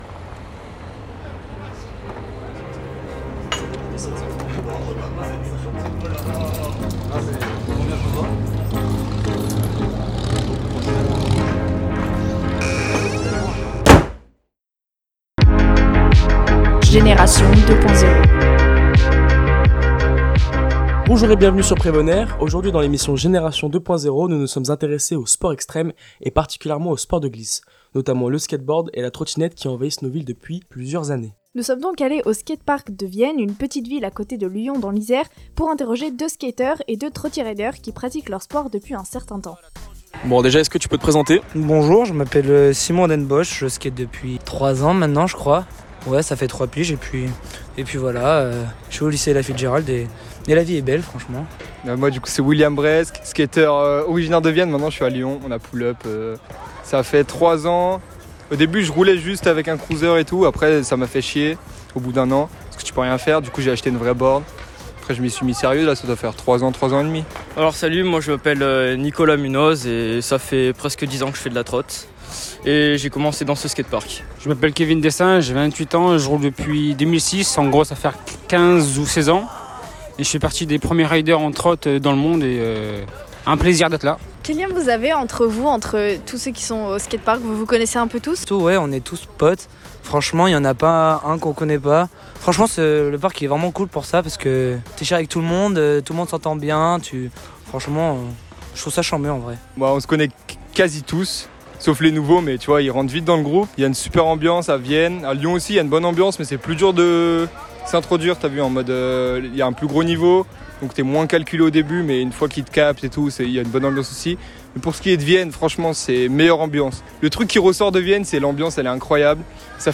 À : Vienne
Un micro-trottoir pour vous faire découvrir la culture skate.